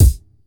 Game Kick3.wav